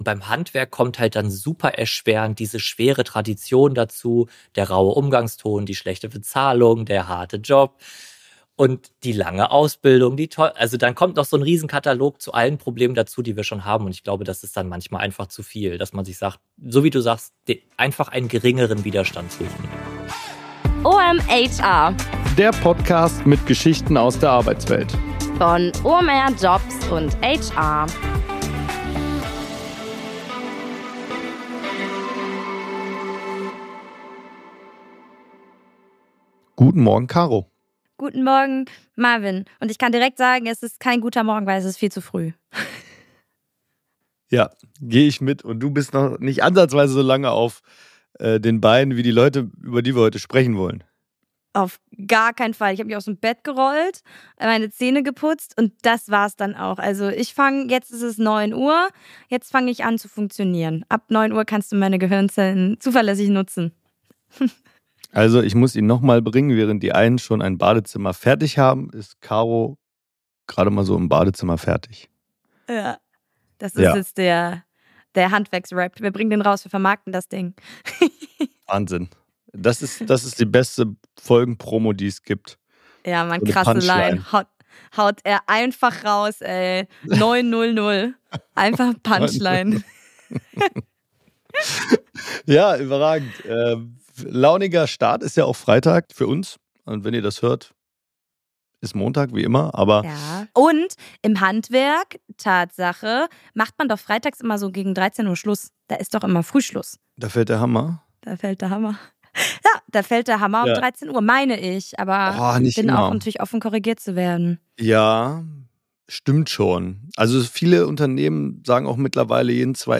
Weiter angereichert wird diese Folge von Gesprächen mit Menschen aus der Branche (Maler und Lackierer, Bauleiter, Heizung und Sanitär).